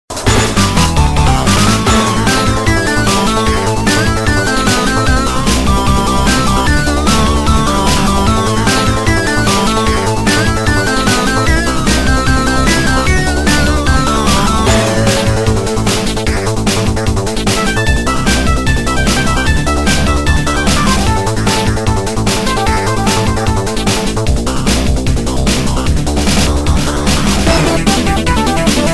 8bit